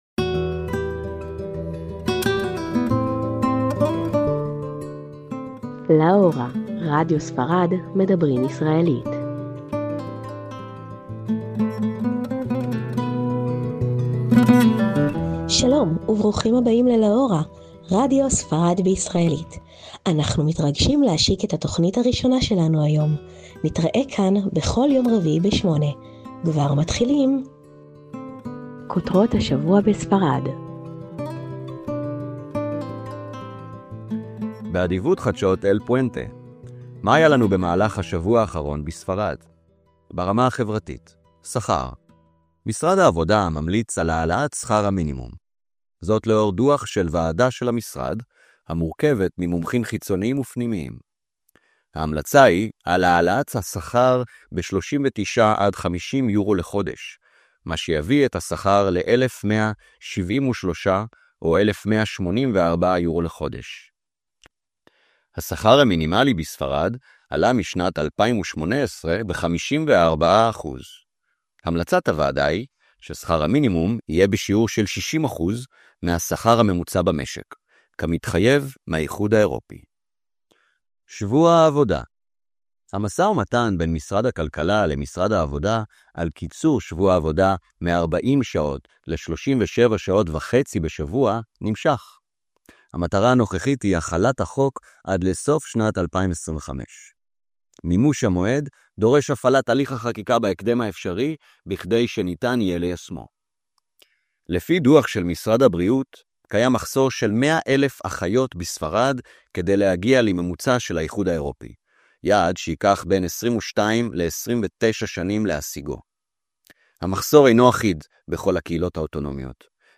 בפרק הראשון אנחנו מארחים את דן פורז, הממונה הזמני על שגרירות ישראל בספרד לשמוע על תפקיד השגרירות ואת הזוית שלו על כל המערכת יחסים בין ישראל לספרד.